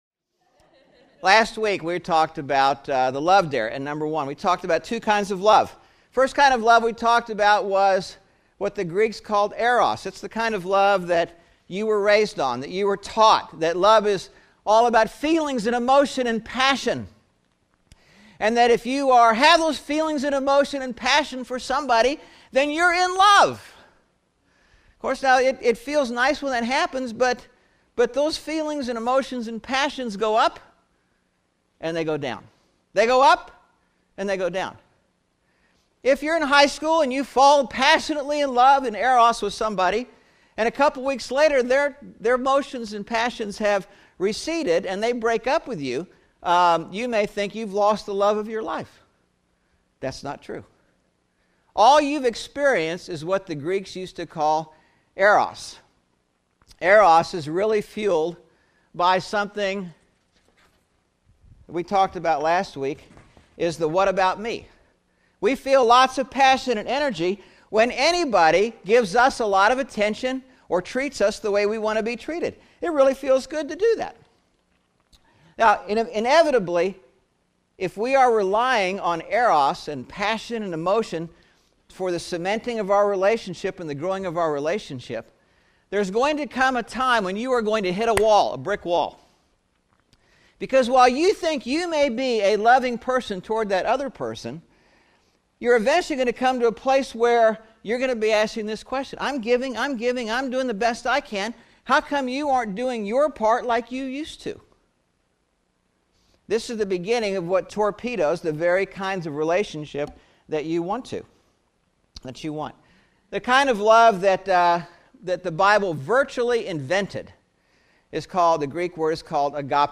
5/1/11 Sermon (Love Dare part 2) – Churches in Irvine, CA – Pacific Church of Irvine